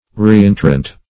Reentrant \Re*en"trant\ (-trant), a.